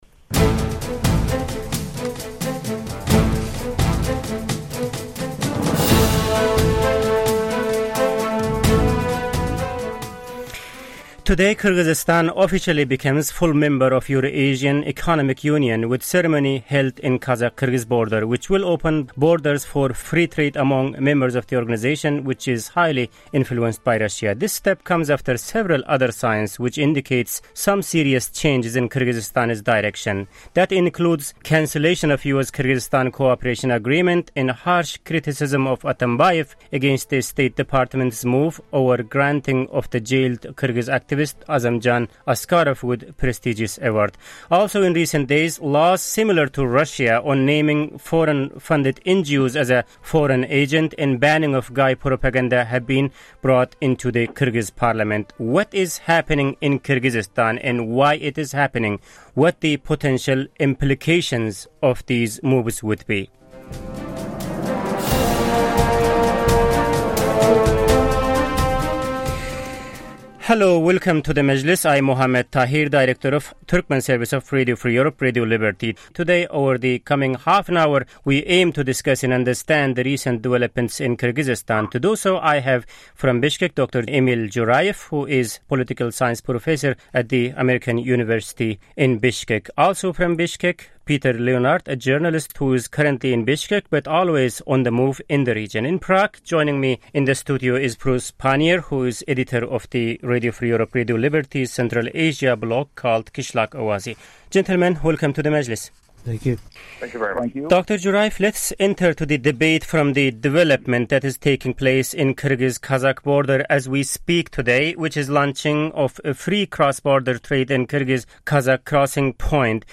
RFE/RL’s Turkmen Service, known locally as Azatlyk, assembled a panel to look at Kyrgyzstan’s decision to join the Eurasian Economic Union and consider some of the pros and cons of the move.